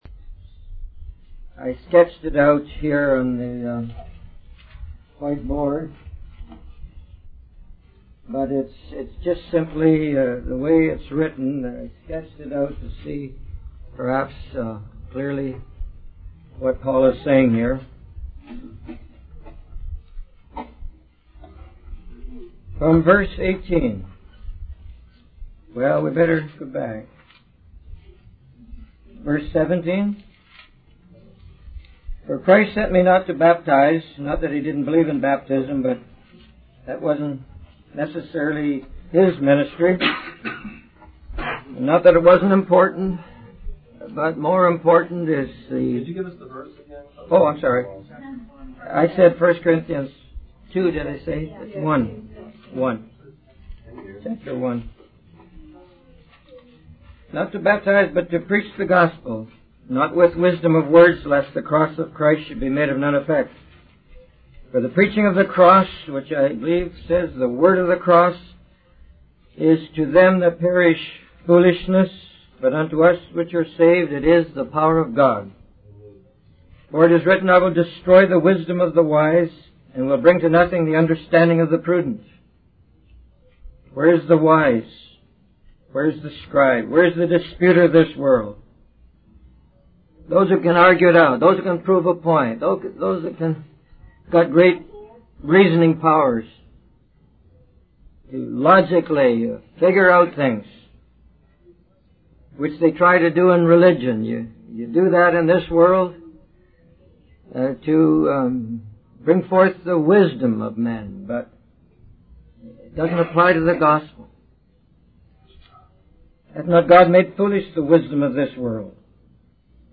In this sermon, the speaker discusses the stumbling and falling of people when it comes to recognizing the ultimate sign of God's power, which is the death of Jesus on the cross.